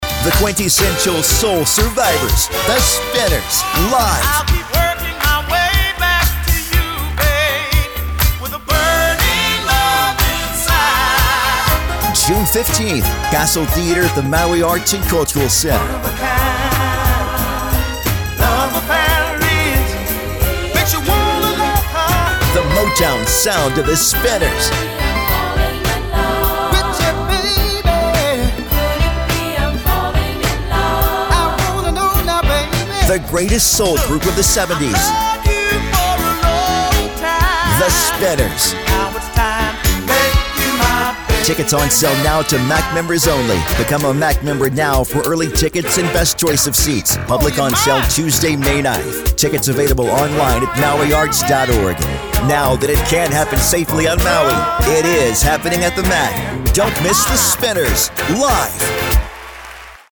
the lush sound of Philly Soul in the 1970s